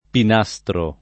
[ pin #S tro ]